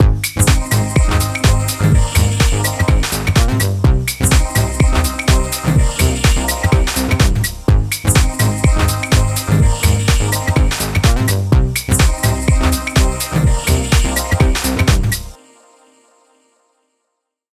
Finally with my vocoder working satisfactorily and my musical elements in place I added a small amount of delay to the vocoded signal to open things up a touch and add some space.
Compression and delay are added.
The final result with some effects added.